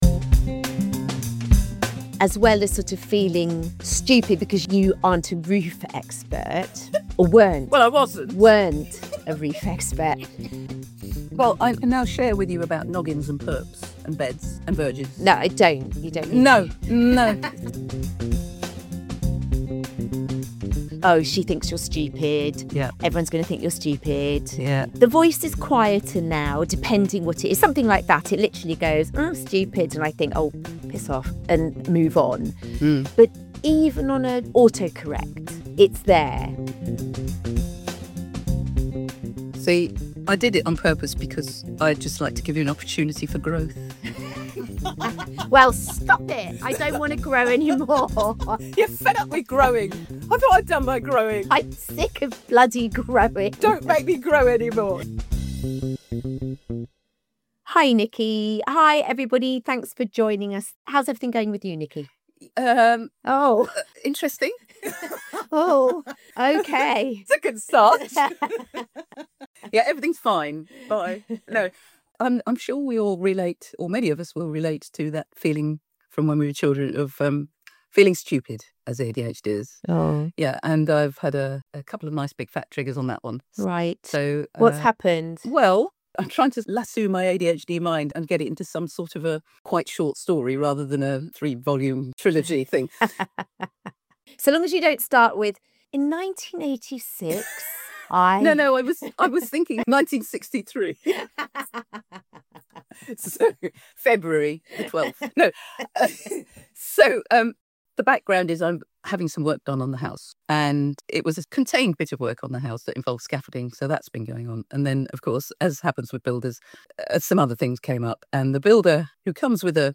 Welcome to The ADHD Connection – 2 ADHD coaches figuring it out together!